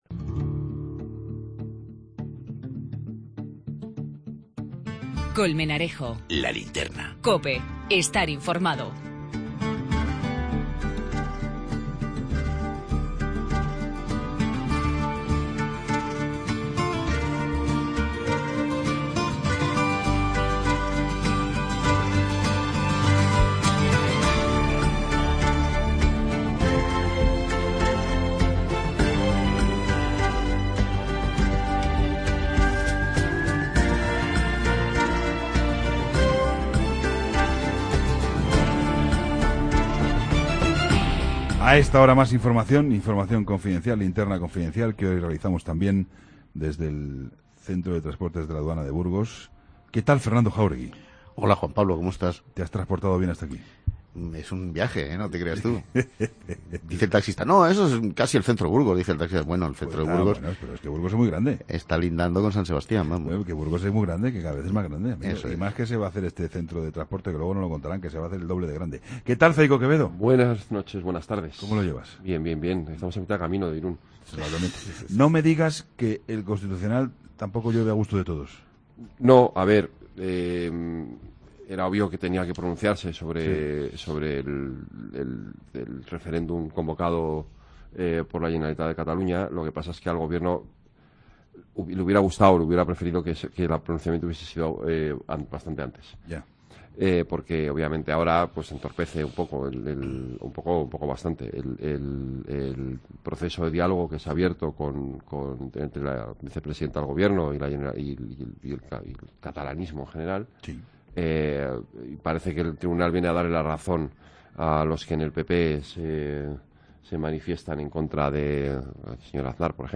Entrevista a Baudilio Fernández-Mardomingo, Delegado Territorial de la Junta de Castilla y León en Burgos.